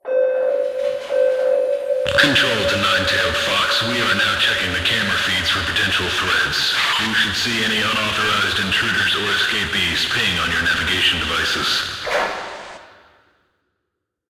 AnnouncCameraCheck.ogg